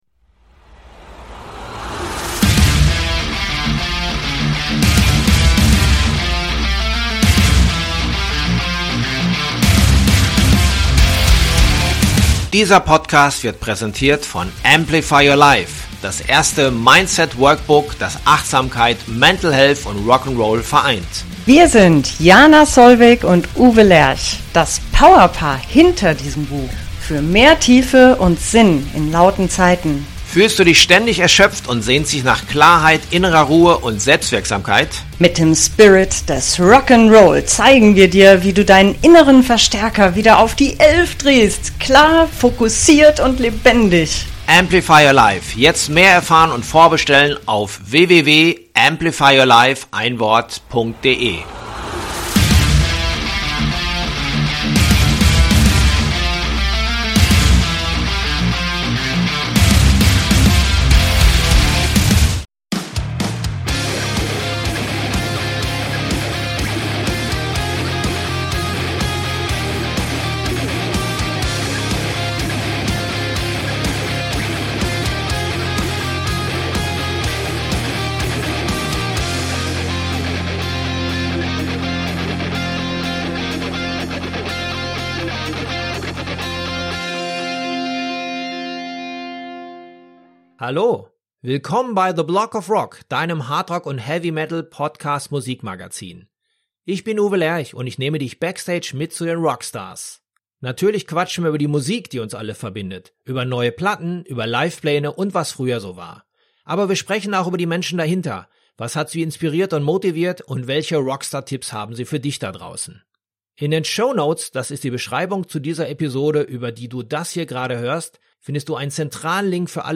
Es war also wieder Zeit für einen neuen Plausch mit dem Meister, den ich in einer musikalischen Mid-Life-Crisis erwischte.